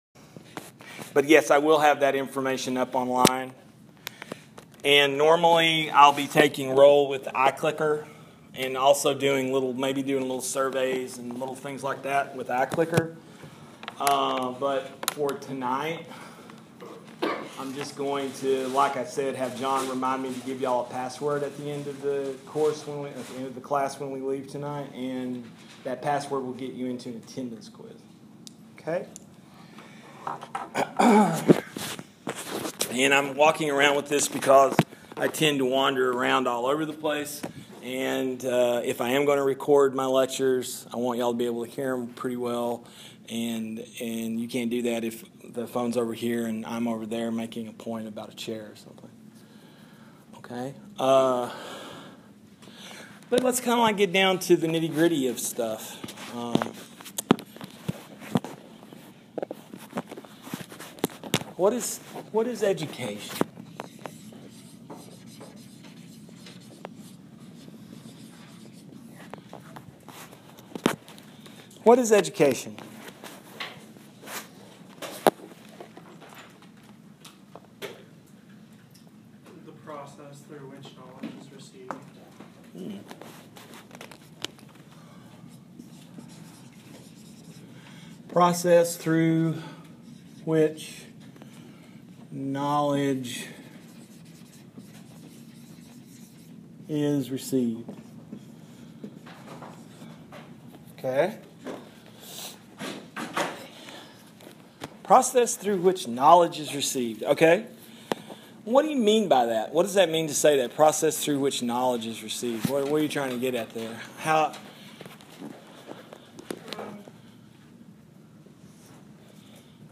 First Monday evening class for Fall 2016, Intro to Philosophy.
After lecturing for about 35 minutes, I turned off the recorder and opened up the room to discussion.